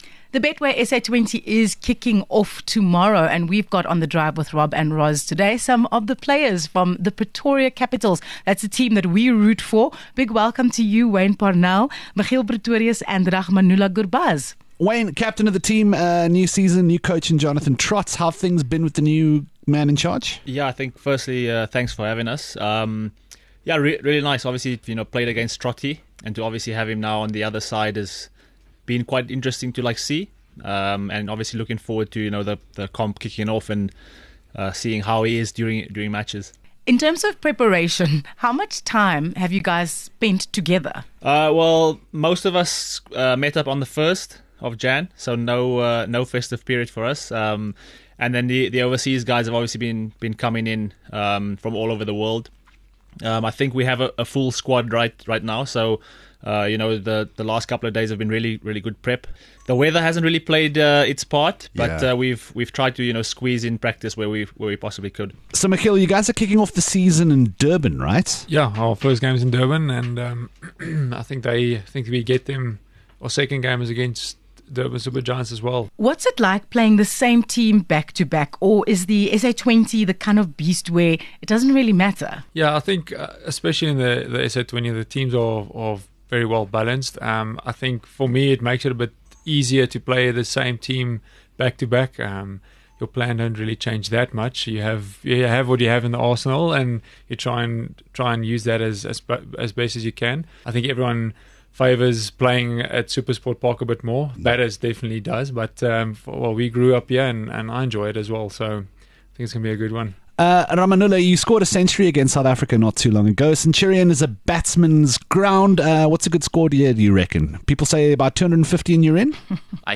8 Jan Prepping for Glory: A chat with Pretoria Capitals